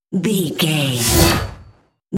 Whoosh electronic fast
Sound Effects
Atonal
futuristic
high tech
whoosh
sci fi